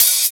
74 OP HAT.wav